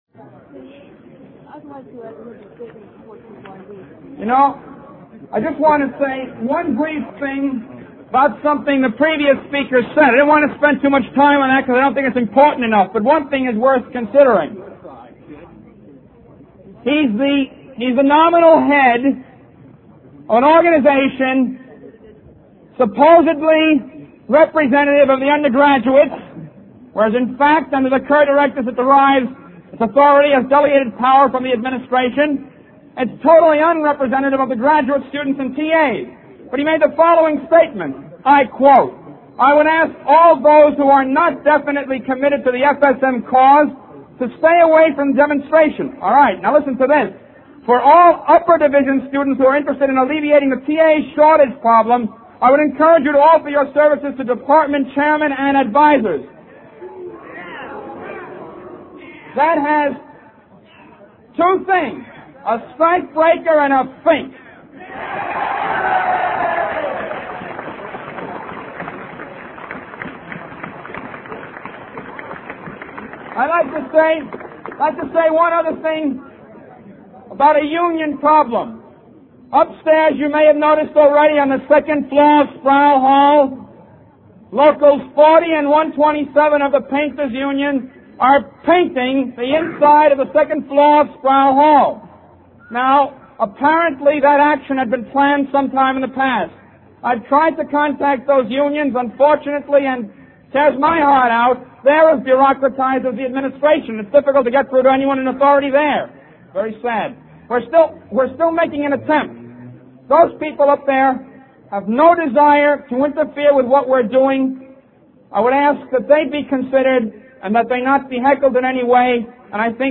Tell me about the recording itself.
Sit-in Address on the Steps of Sproul Hall delivered 2 December 1964, The University of California at Berkeley